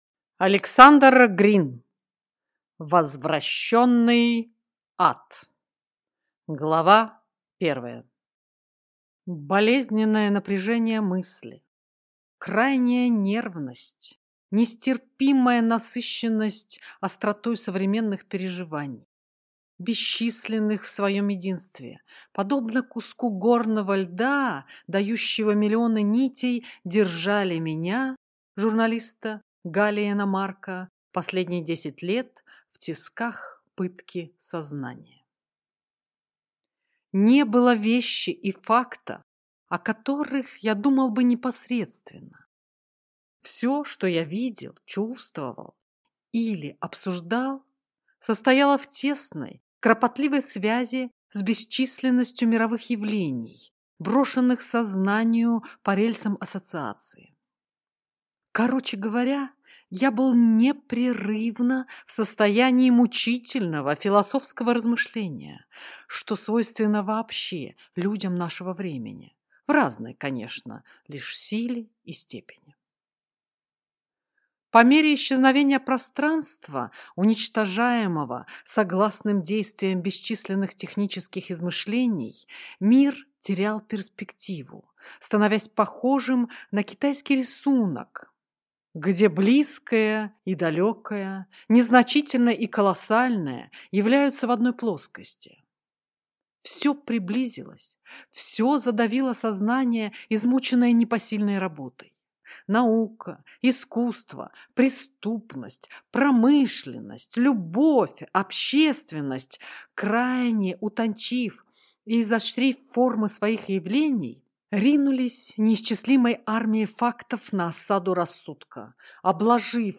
Аудиокнига Возвращенный ад | Библиотека аудиокниг